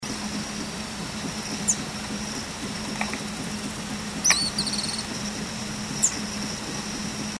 Straneck´s Tyrannulet (Serpophaga griseicapilla)
Life Stage: Adult
Location or protected area: Reserva Natural Urbana La Malvina
Condition: Wild
Certainty: Recorded vocal